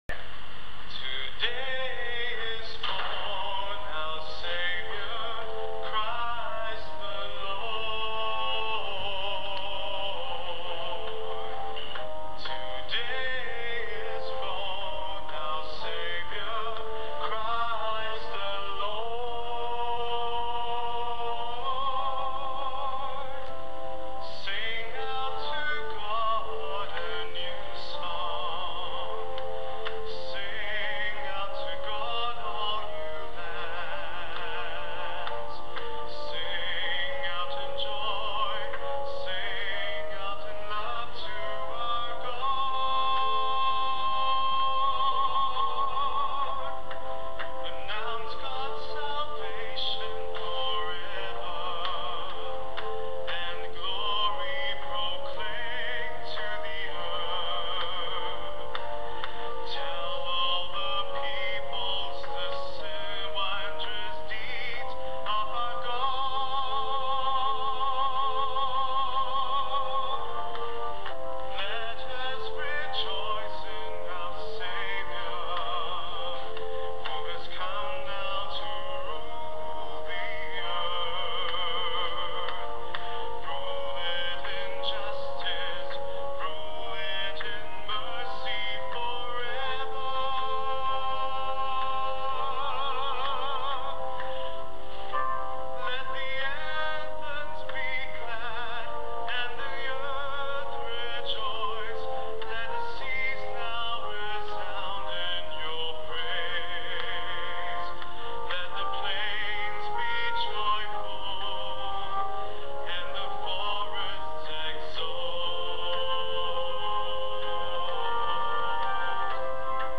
Christmas Midnight Mass, 25 December
Psalm Gospel Acc